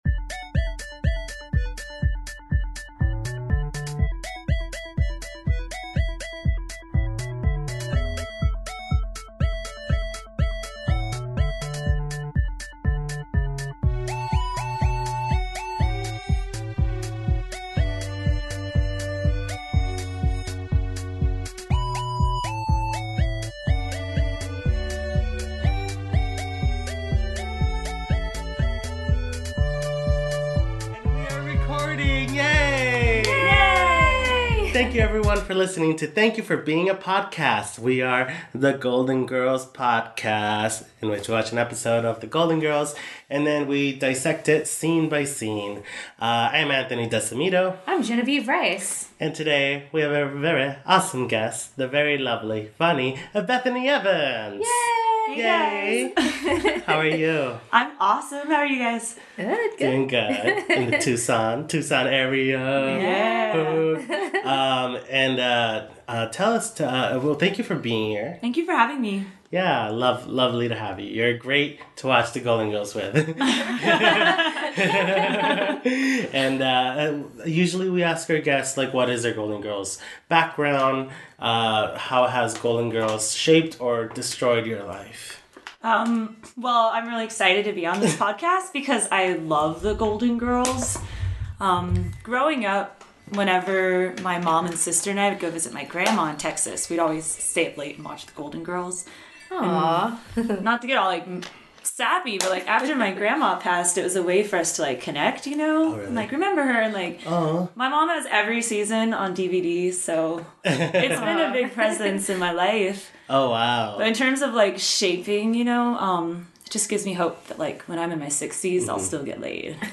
You can hear the beautiful wind chimes outside as we watched the one where Dorothy and Stan get audited from super risky purchases during their marriage. We talked about E. Honda from Street Fighter, accounting dresses and how pawn shops work.